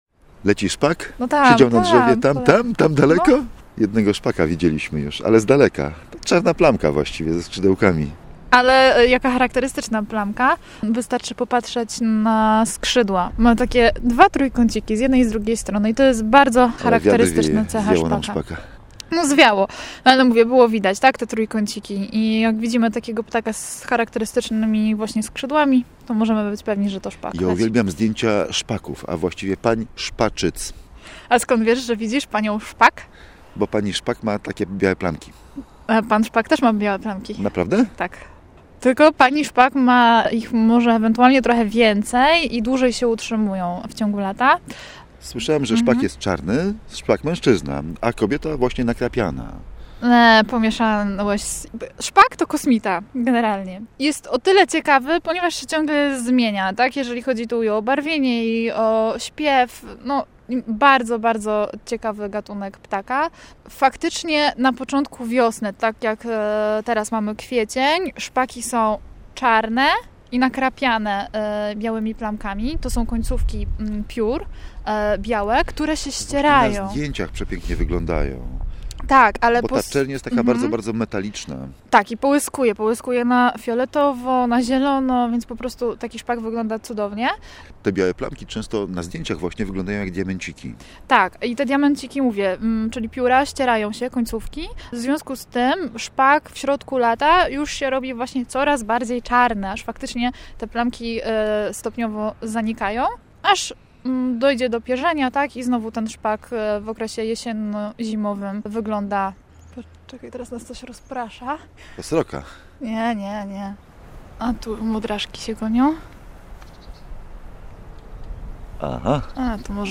Audycja rozpoczyna się od opisu wyglądu szpaków.